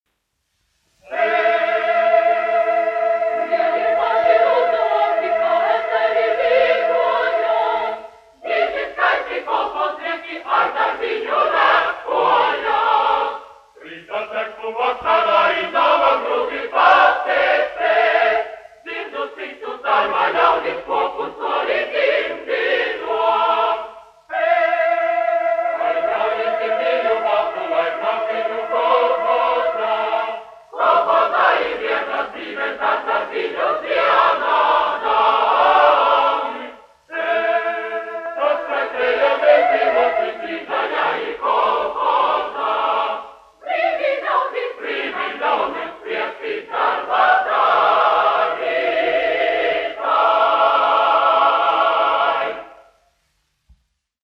Lieli, plaši rudzu lauki : latviešu tautasdziesma
Jēkabs Mediņš, 1885-1971, aranžētājs
Latvijas Radio koris, izpildītājs
Kalniņš, Teodors, 1890-1962, diriģents
1 skpl. : analogs, 78 apgr/min, mono ; 25 cm
Kori (jauktie)
Latvijas vēsturiskie šellaka skaņuplašu ieraksti (Kolekcija)